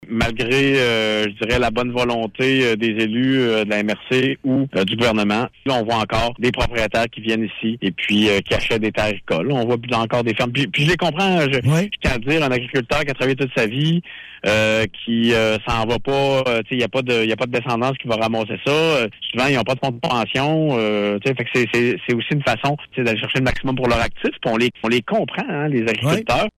En entrevue mercredi dans Réveil Rimouski, le préfet dénonce cet état de fait, mais dit comprendre que le manque de relève force certains producteurs à vendre au plus offrant.